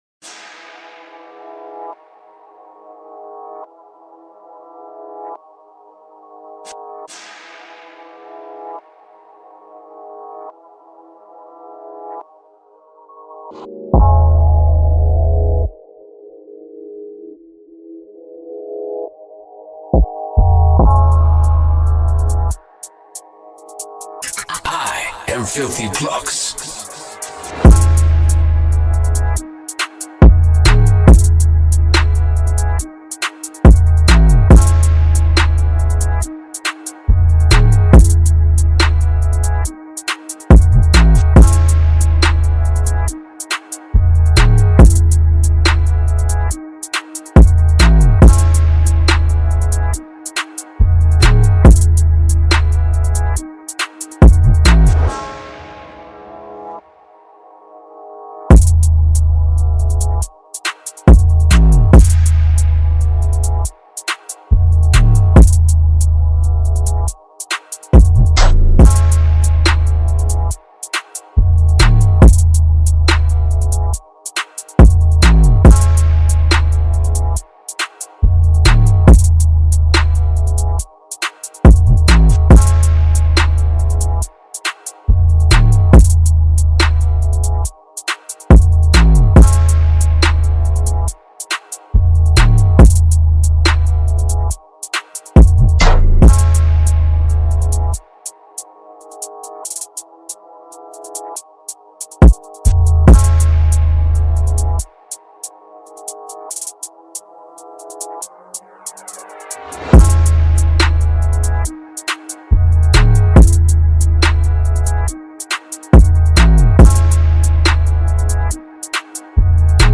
official instrumental
2021 in NY Drill Instrumentals